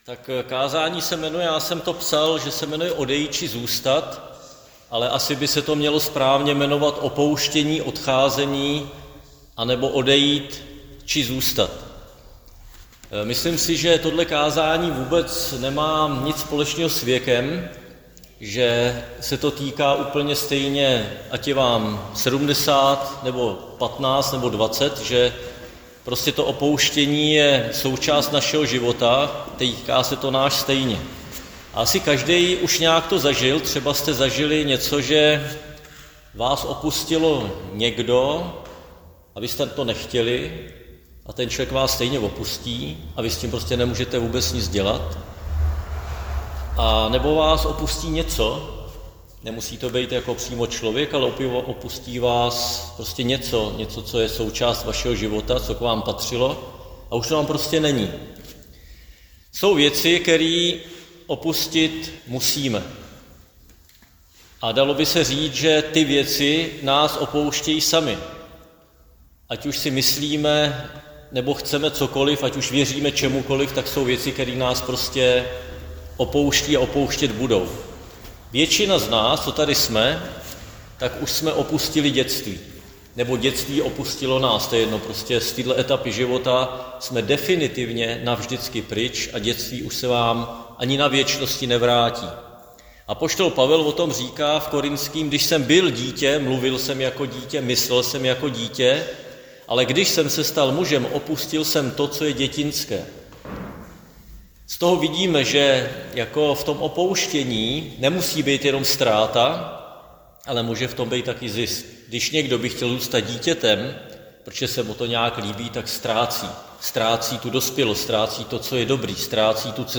Křesťanské společenství Jičín - Kázání